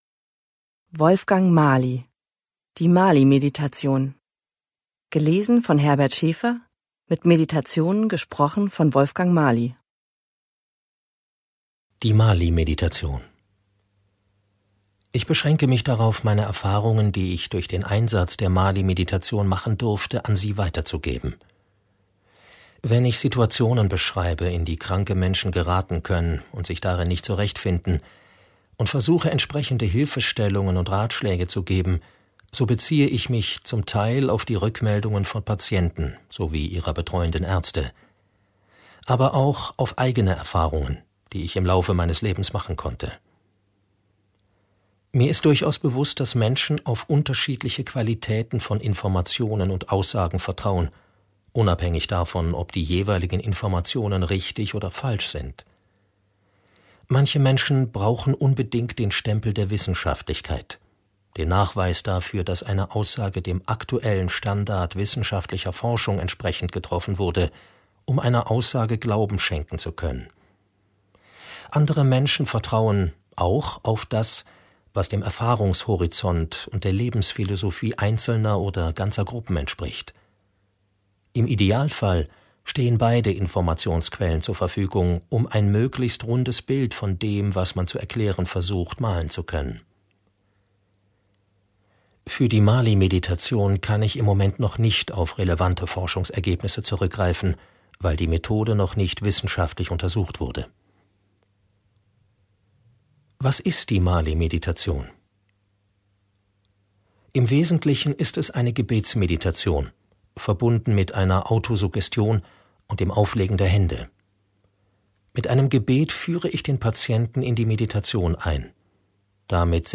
Hörbuch
Schlagworte Alltag • Anleitung • ARD • Das Geheimnis der Heilung • Gebetsmeditation • Geführte Meditation • Gesundheit • Heilmeditation • Heilmeditation; Ratgeber • Joachim Faulstich • Krebs • Meditation; Ratgeber • Visualisierung